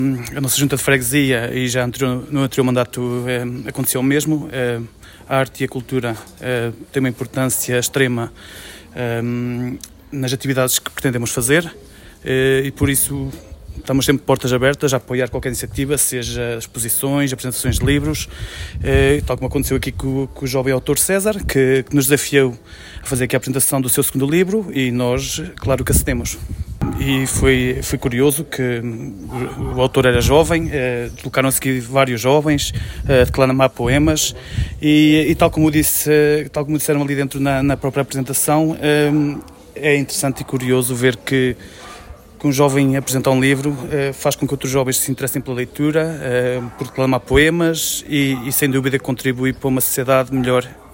Para o presidente da Junta de Freguesia de Macedo de Cavaleiros, David Vaz, o apoio a iniciativas culturais continua a ser uma prioridade: